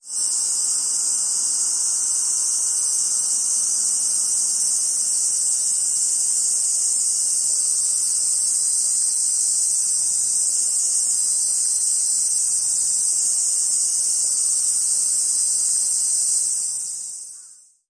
Summer Insects.mp3